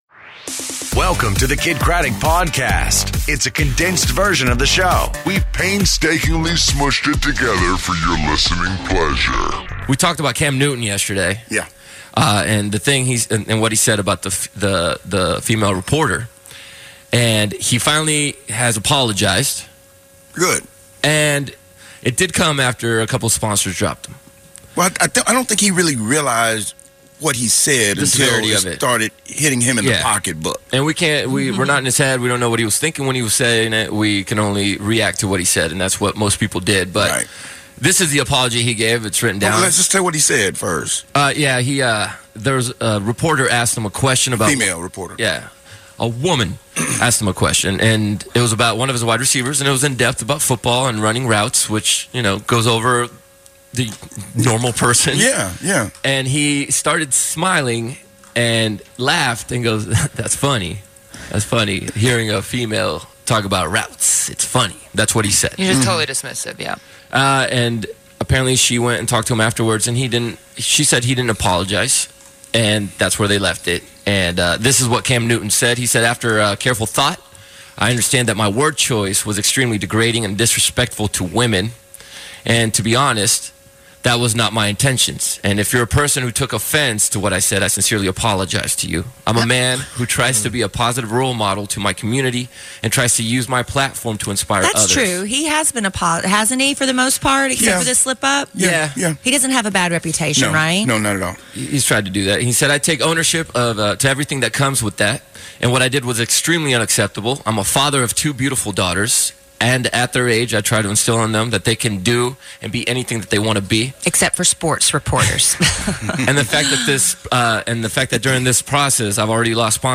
Live From Austin For ACL